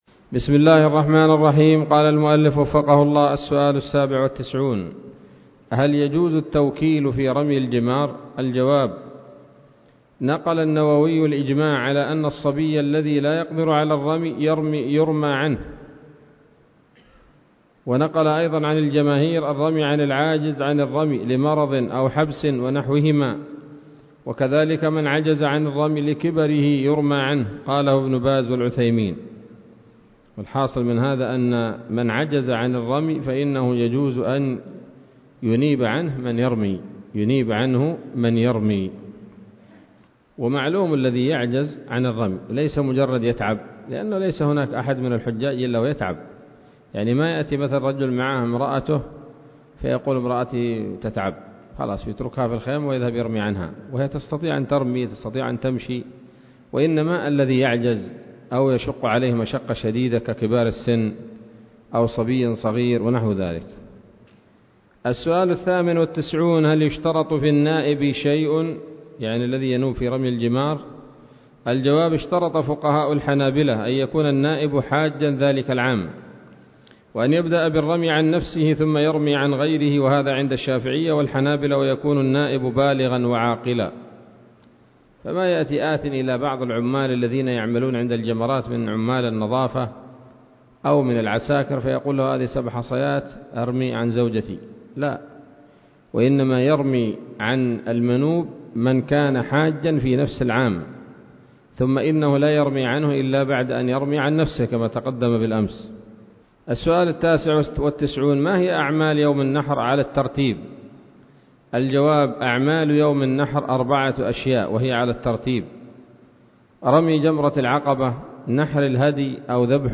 الدرس الثامن والثلاثون من شرح القول الأنيق في حج بيت الله العتيق